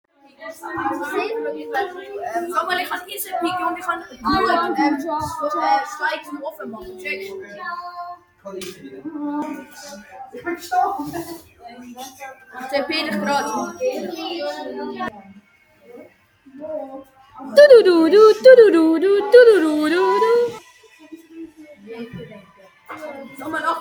Goofy Sound Effects MP3 Download Free - Quick Sounds